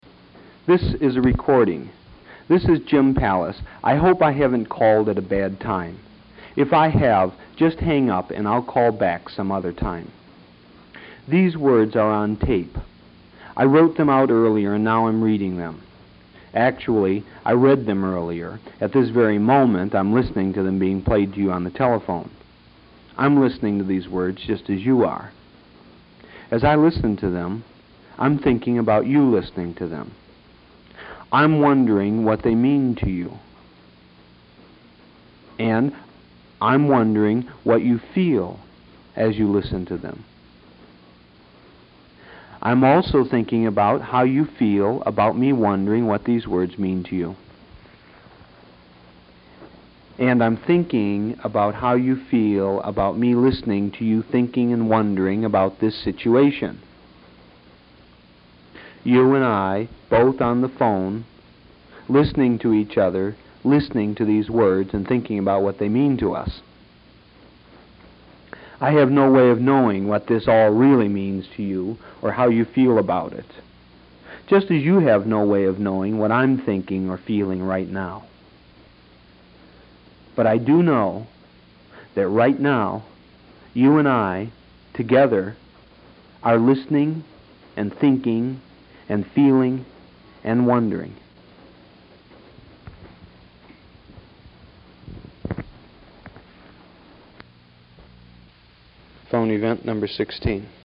The Phoneyvent would be cued up on an audio cassette tape player.